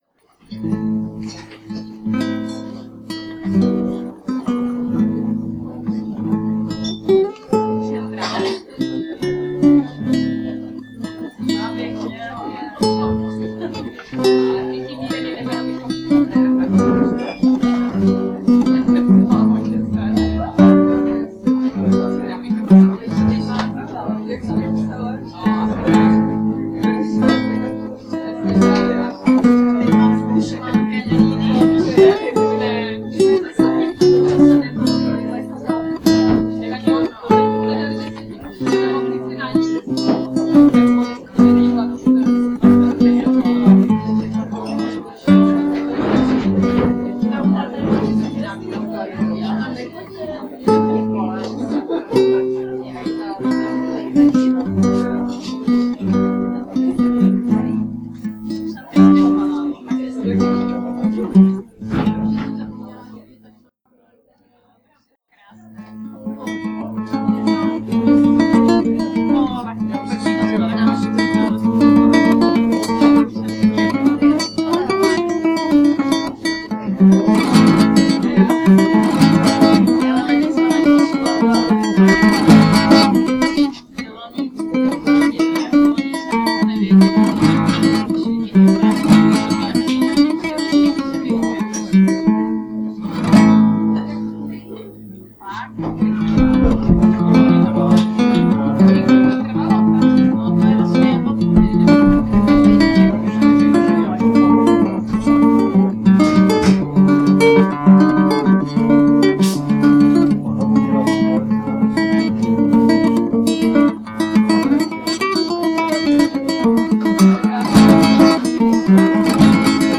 Kytarové sólo